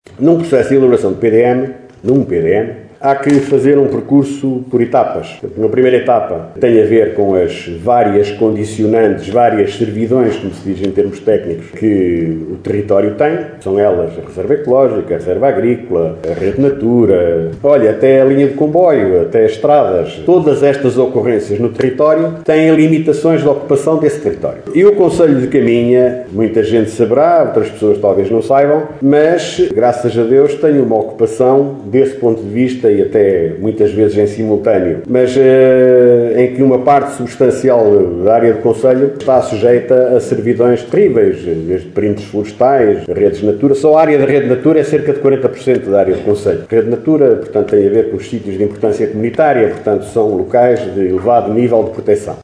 Guilherme Lagido explicou aos deputados eleitos para a Assembleia Municipal de Caminha, durante o último encontro daquele órgão autárquico, que as condicionantes com a Reserva Ecológica, Reserva Agrícola ou Rede Natura já estão, a maioria, definidas.